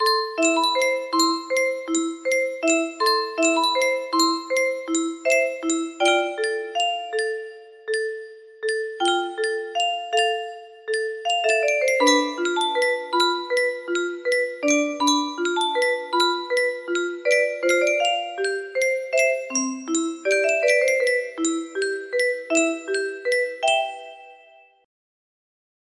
Love song music box melody